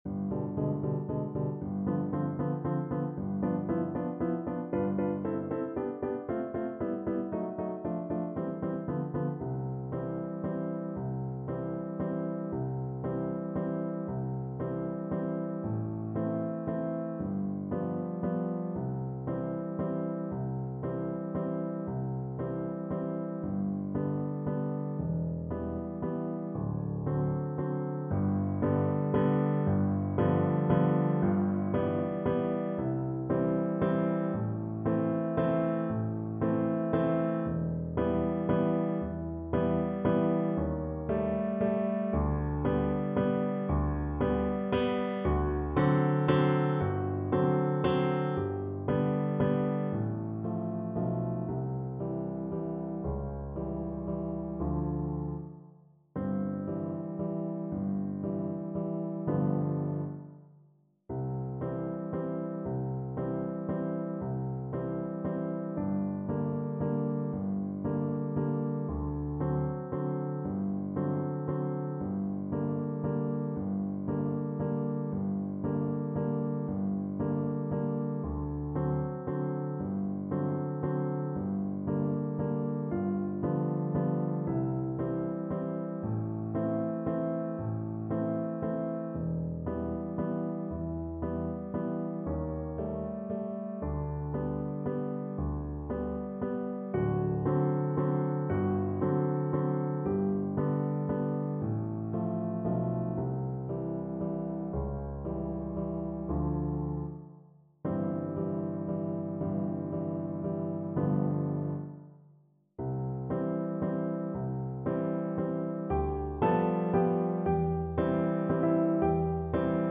Cello
3/4 (View more 3/4 Music)
C major (Sounding Pitch) (View more C major Music for Cello )
Adagio =50
Classical (View more Classical Cello Music)